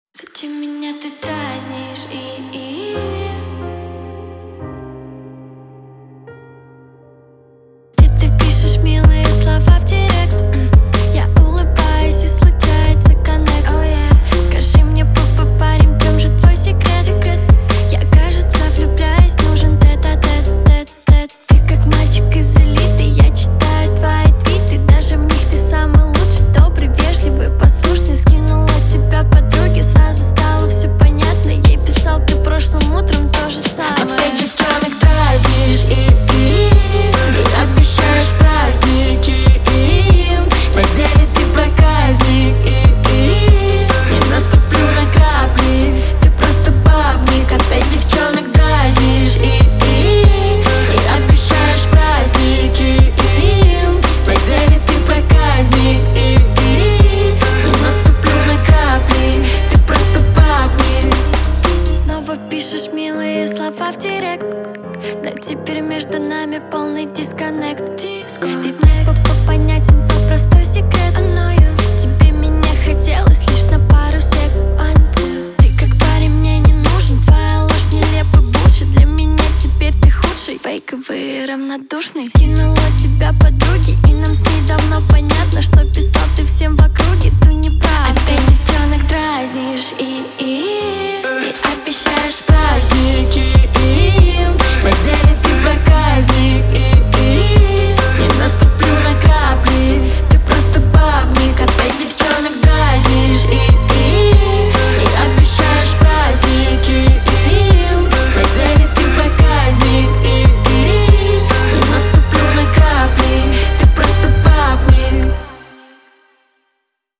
динамичная поп-песня
отличается яркими синтезаторами и ритмичными битами